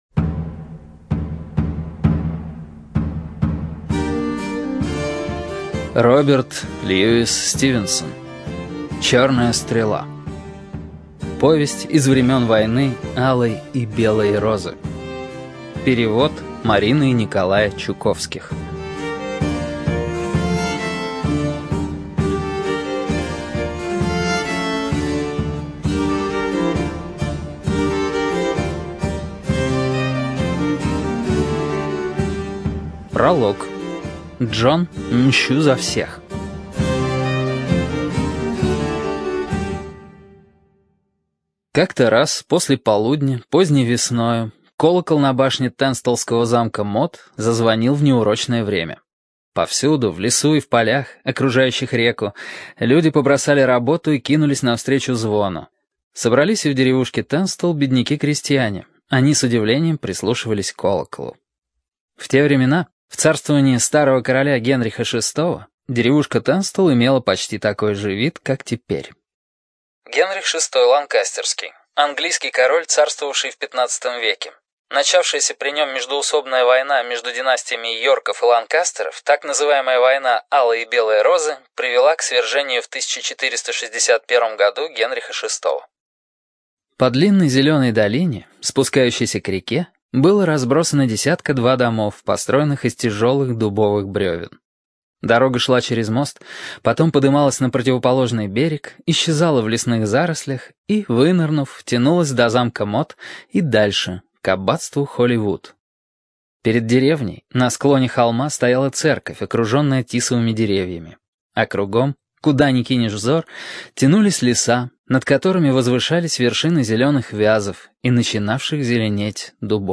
ЖанрПриключения, Историческая проза